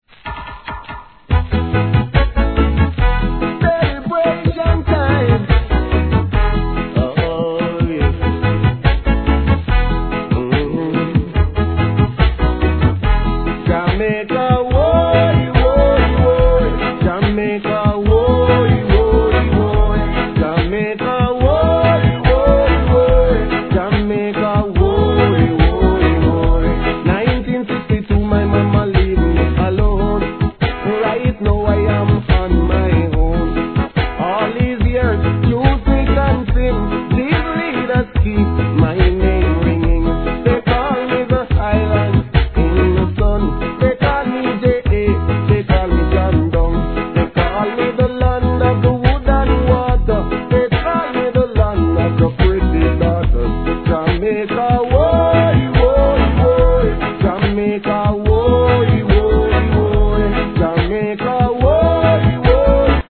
REGGAE
素晴らしいコーラスで聴かせる温かいJAMAICA賛歌♪